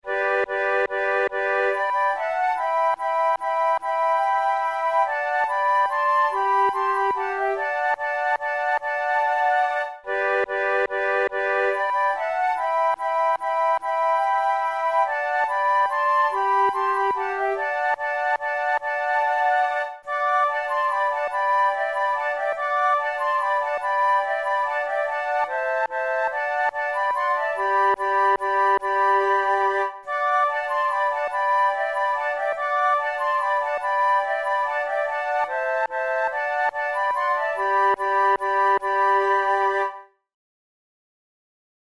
InstrumentationFlute trio
KeyG major
Time signature3/4
Tempo144 BPM
Baroque, Minuets, Sonatas, Written for Flute